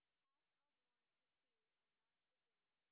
sp14_white_snr0.wav